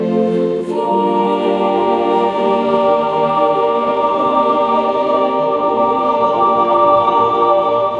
audio-to-audio choral music-generation
MusicGen fine-tuned on chamber choir music
"sacred chamber choir"